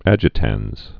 (ăjĭ-tănz)